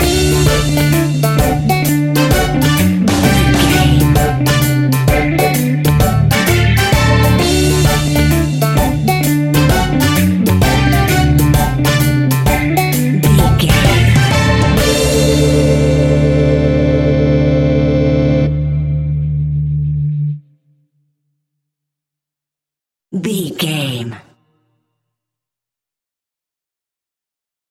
Ionian/Major
D
dub
laid back
chilled
off beat
drums
skank guitar
hammond organ
percussion
horns